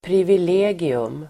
Uttal: [privil'e:gium]